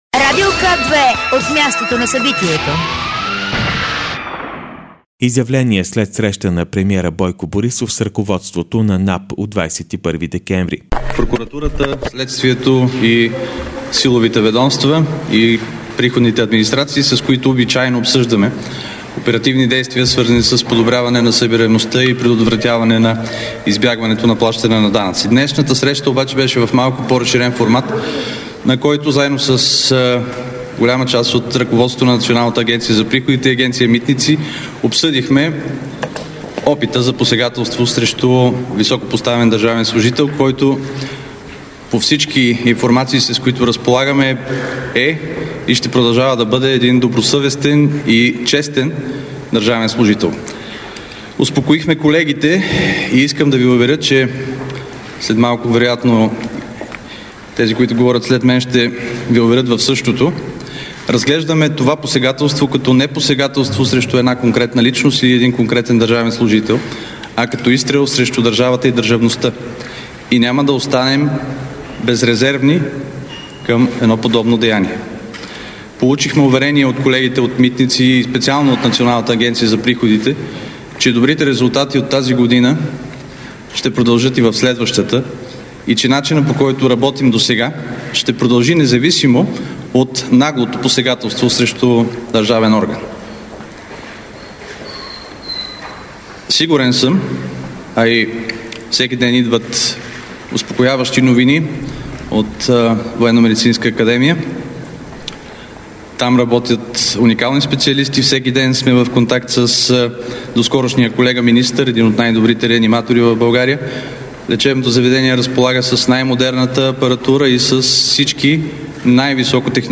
Радио К2 - директно от мястото на събитието
11.35 - Изявления след среща на правителството и премиера Бойко Борисов с ръководството на НАП.